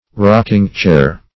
rocking-chair.mp3